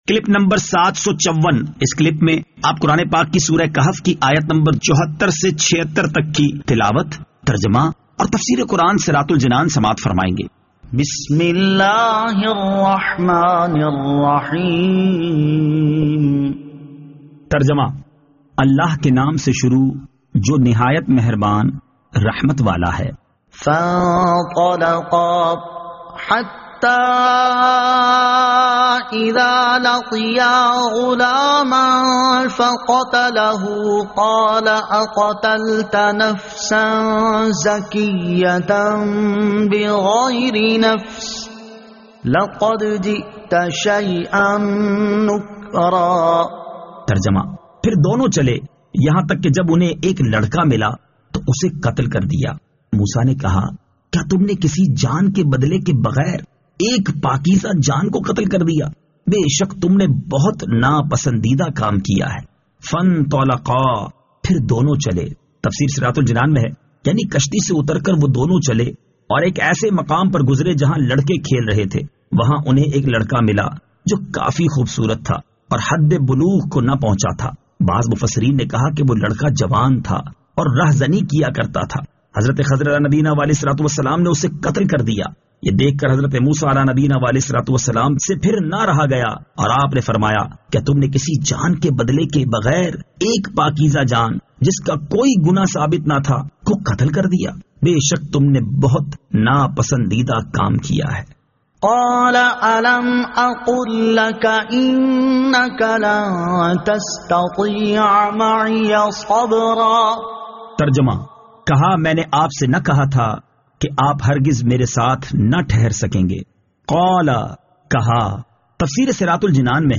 Surah Al-Kahf Ayat 74 To 76 Tilawat , Tarjama , Tafseer